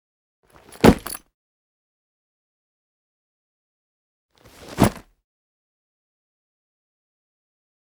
household
Garment Bag Put Down